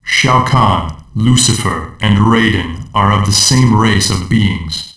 mkd-lucifer-backwards.wav